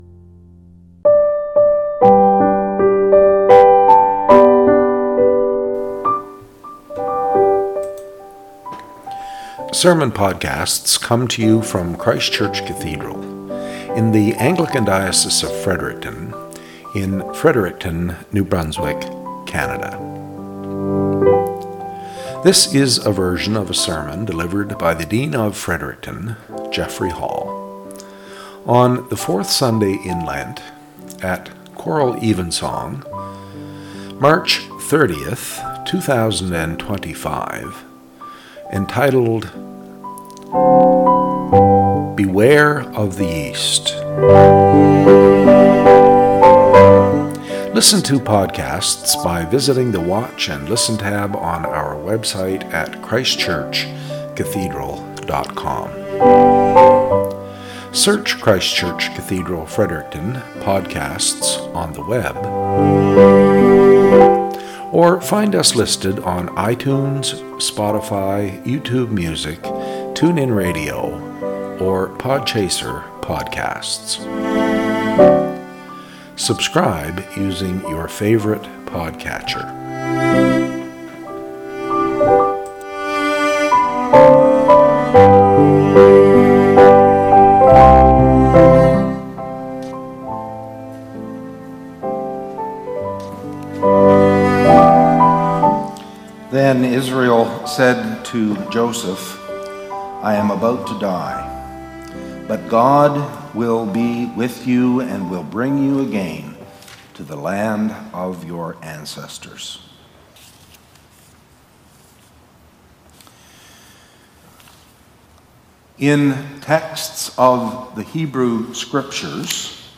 Cathedral Podcast - SERMON -
Podcast from Christ Church Cathedral Fredericton